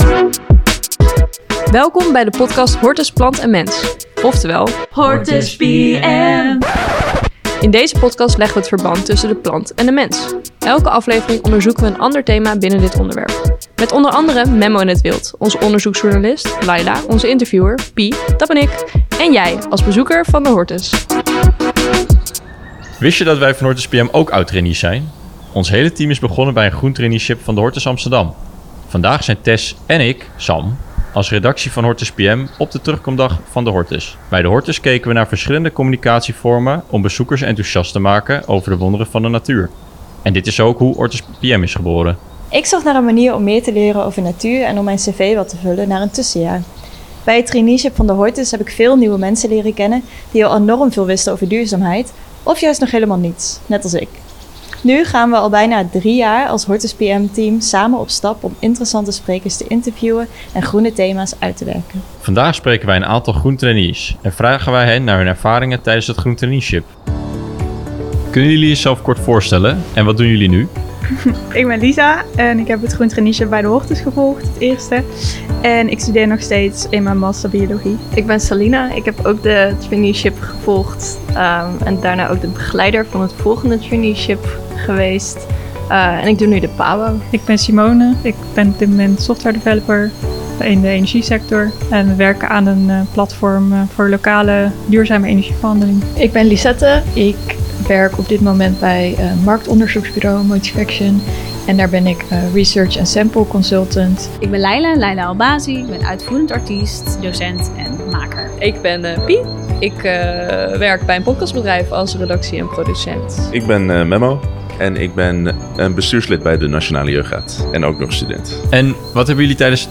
Met dank aan alle ex-groen trainees voor de interviews.